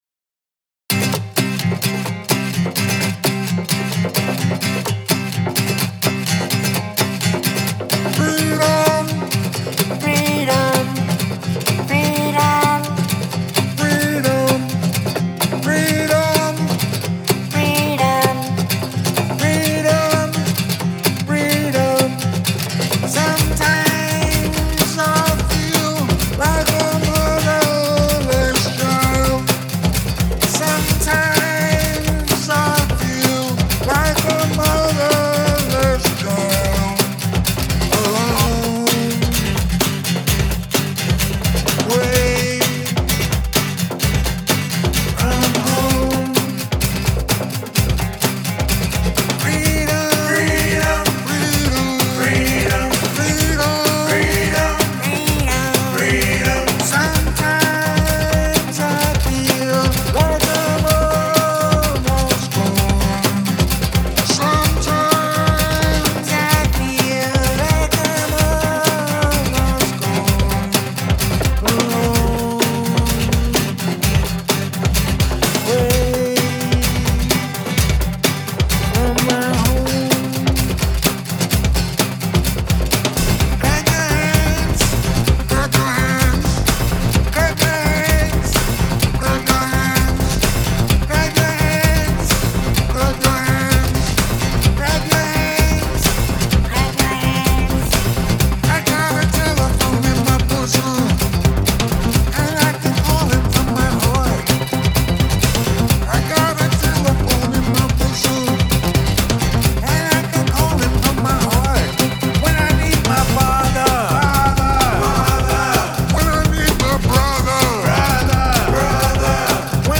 I got the idea to do a cover of it, in an "EDM" style.
I don't like the effected vocals.